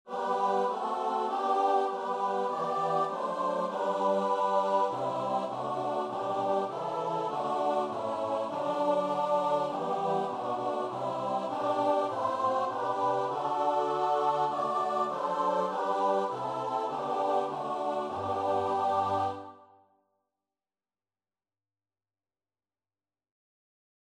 4/4 (View more 4/4 Music)
Choir  (View more Easy Choir Music)
Christian (View more Christian Choir Music)